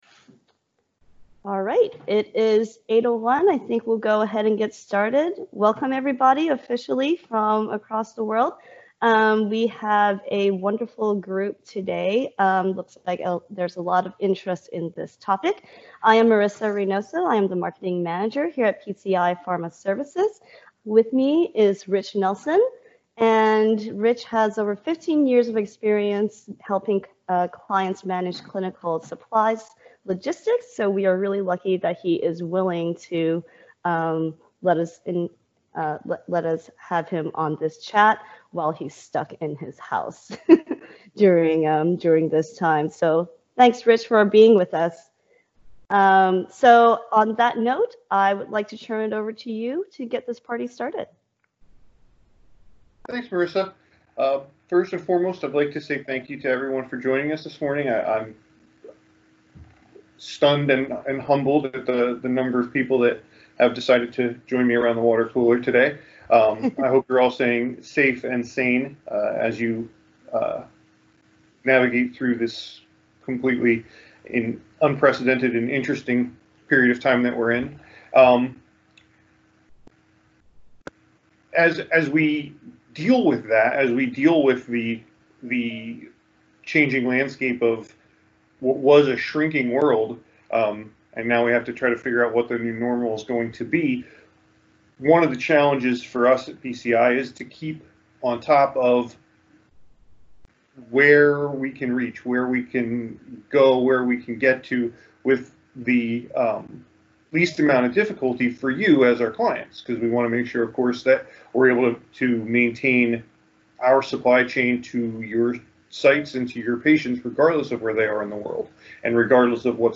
We host a weekly virtual Water Cooler Chat series, where our subject matter experts host informal discussions around a number of clinical supply hot topics.